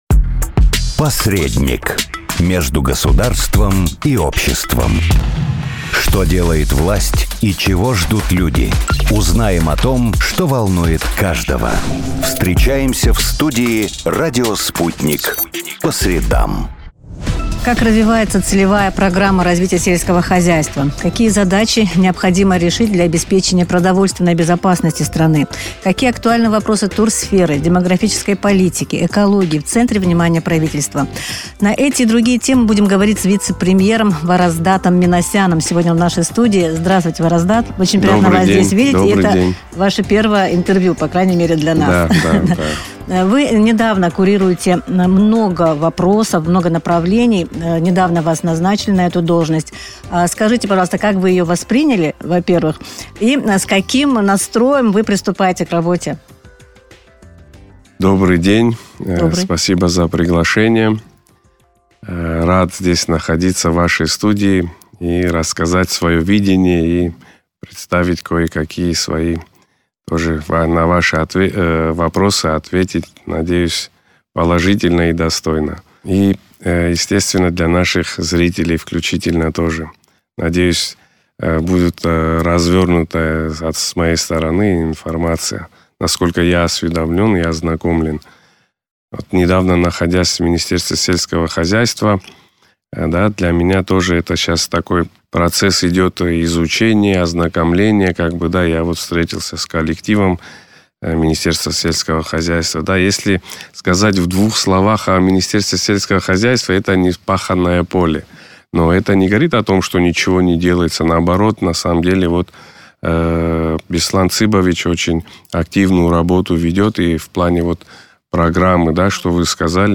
Вараздат Миносян в своем первом интервью Sputnik в качестве вице-премьера рассказал о том, с чего началась его работа и какие задачи он ставит перед собой и ведомствами, которые курирует.